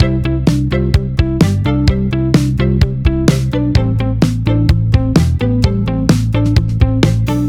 そこでドラム以外の全てのパートを、1/8グリッドで適度にシンコペートさせてみます。
アップビートを強調する打点が増えて、全体的に軽快さが生まれました。